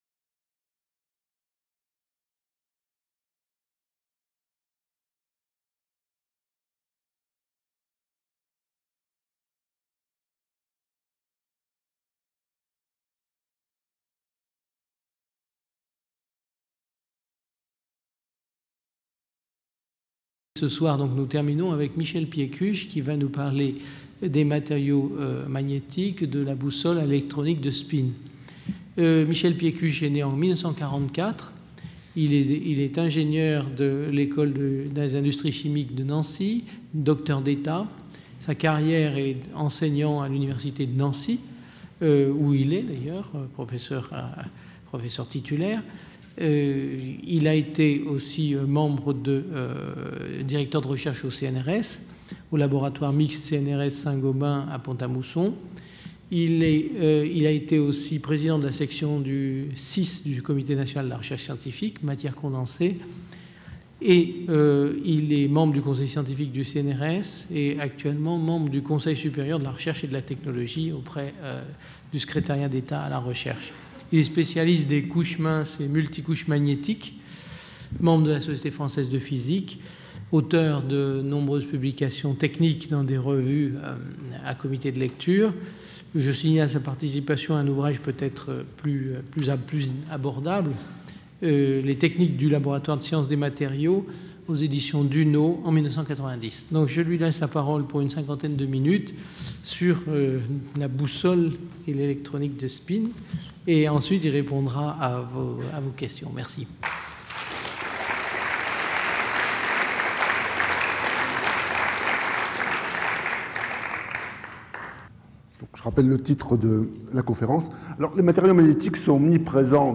Le but de cet exposé est de tenter de lever ces mystères et d'expliquer la formidable importance des matériaux magnétiques dans nos sociétés développées. La conférence va débuter par un bref historique des matériaux magnétiques, depuis leur découverte en Asie mineure et en Chine jusqu'aux développements les plus récents.